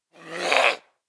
DayZ-Epoch/SQF/dayz_sfx/zombie/spotted_4.ogg at d87bf59dc3ebeb64f5ffd9c5b73b5ca4c885205b